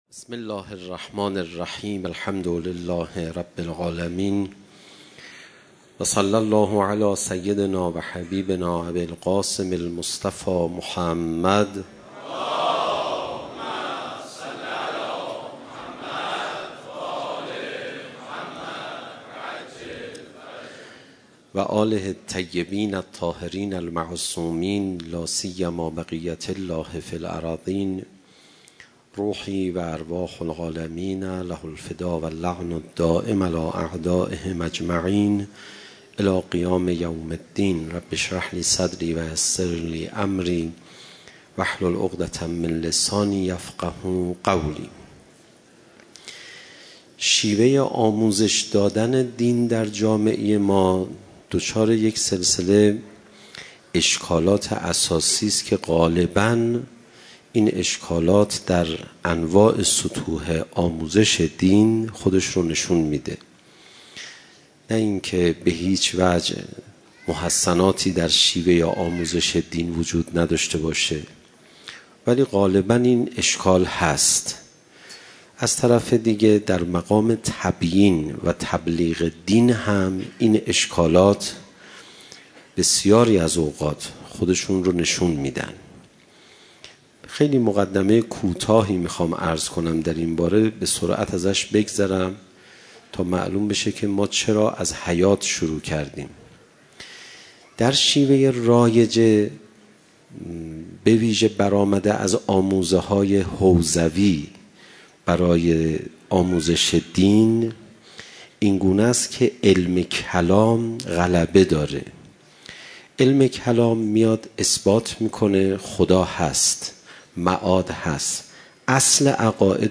شب دوم محرم 96 - هیئت میثاق با شهدا